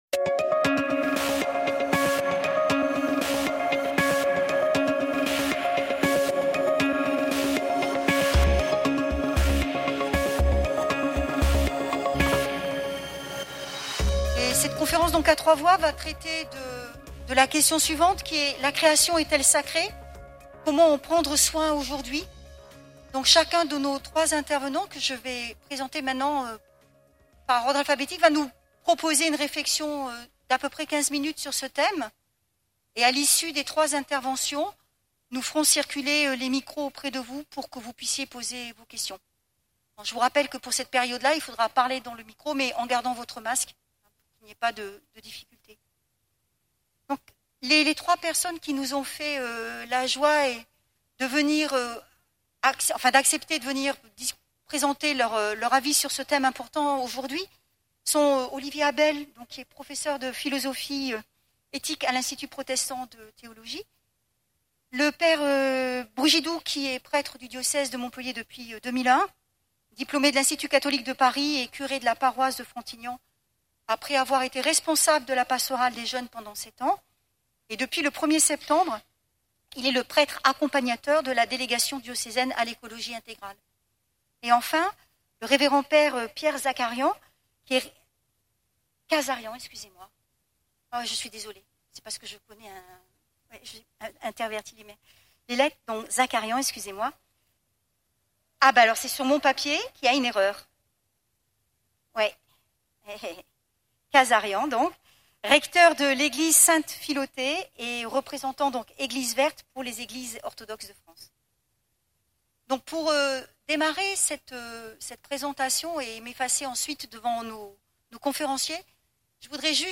Enregistrement par RCF